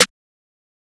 Pluggz Snare.wav